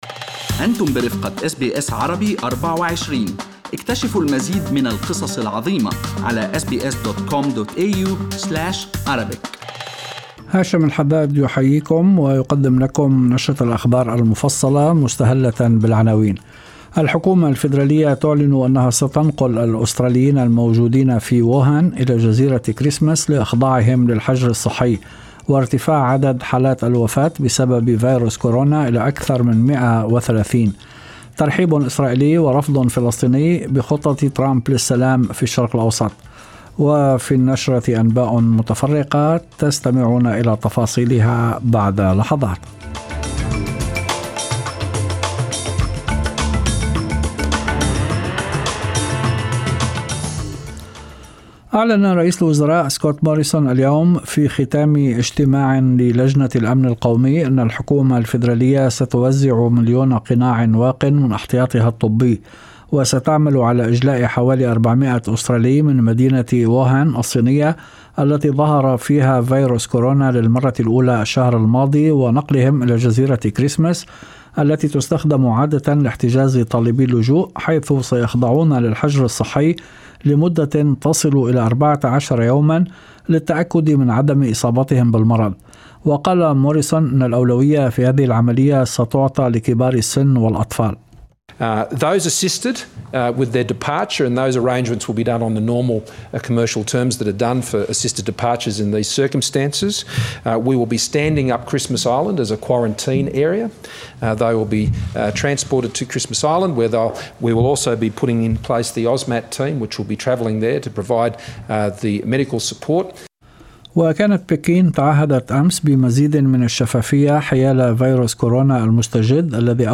Arabic News Bulletin Source: SBS Arabic24